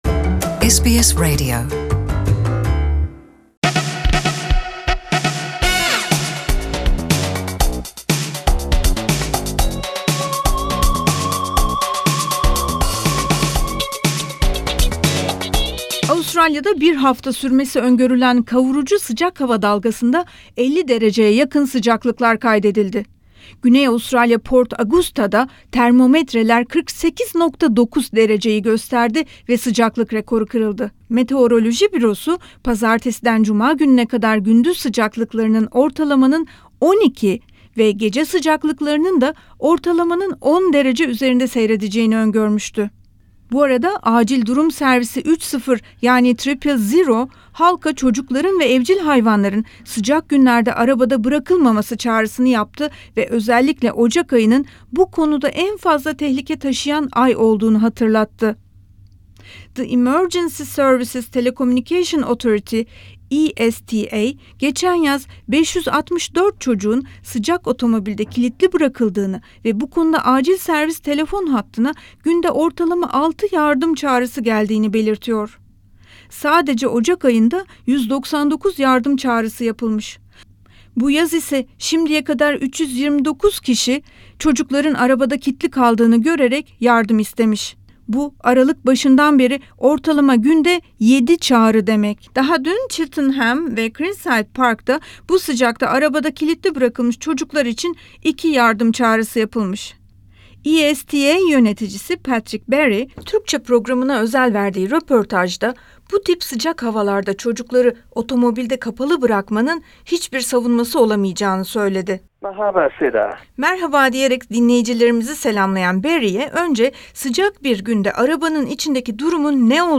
SBS Türkçe radyosuna verdiği röportajda